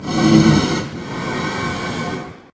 breathe1.ogg